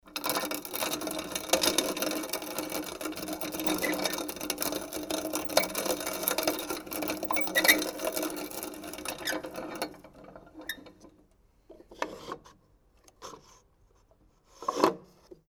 Online-Archiv für verschwindende Geräusche
Kaffeemühle